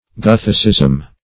Search Result for " gothicism" : The Collaborative International Dictionary of English v.0.48: Gothicism \Goth"i*cism\, n. 1.